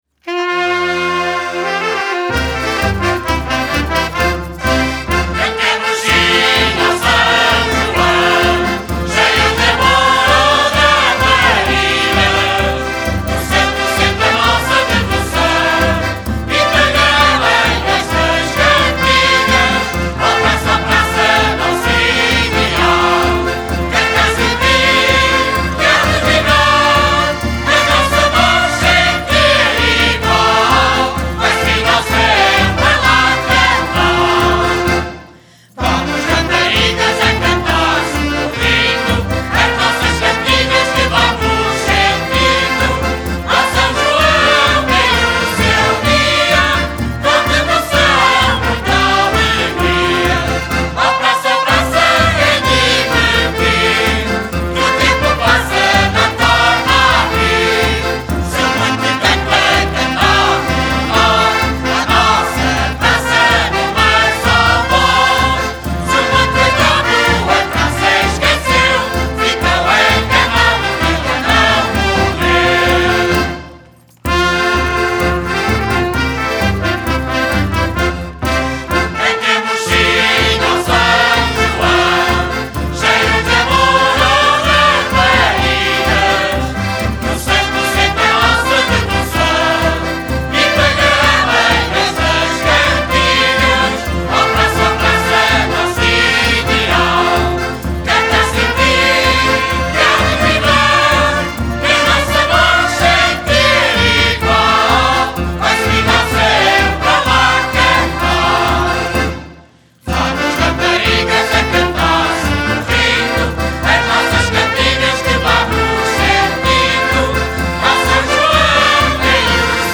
(Marcha)